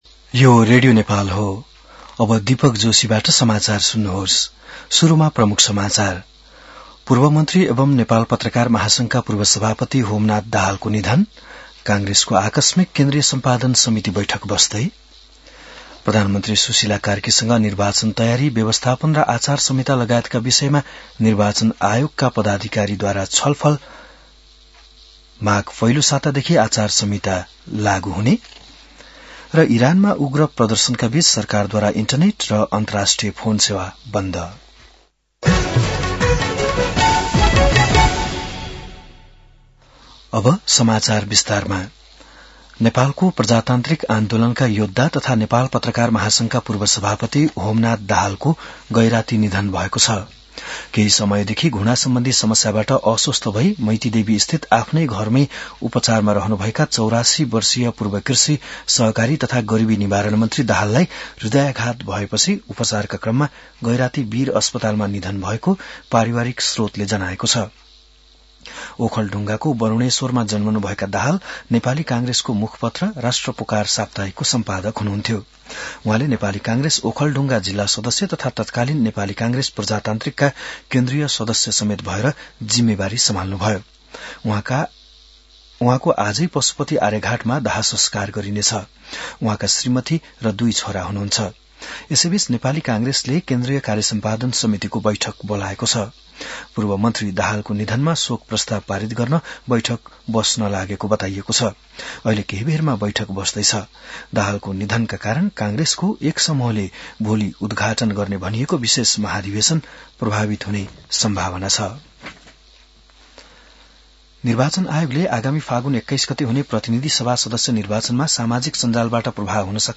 बिहान ९ बजेको नेपाली समाचार : २६ पुष , २०८२